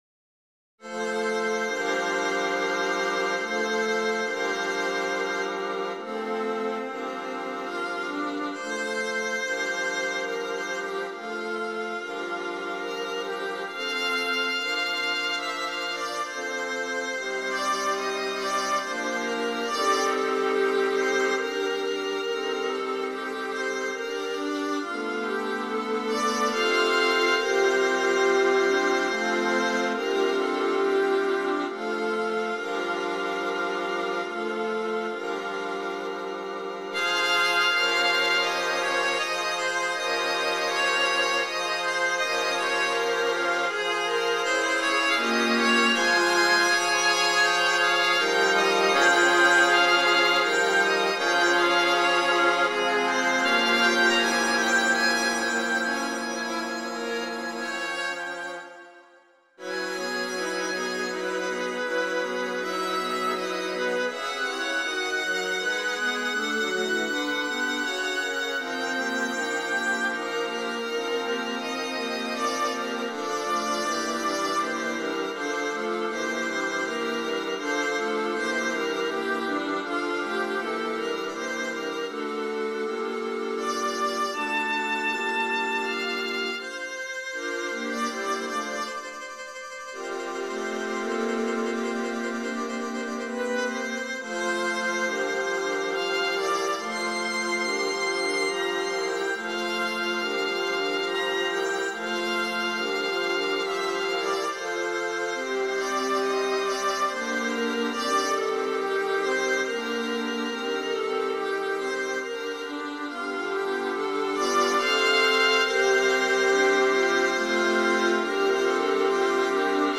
The 1st player carries the melody throughout. 3 mins.